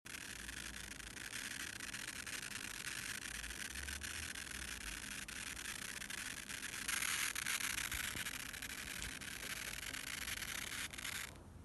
Треск из твиттера
Треск есть в том числе без какого-либо источника звука ( т.е колонка просто воткнута в сеть ).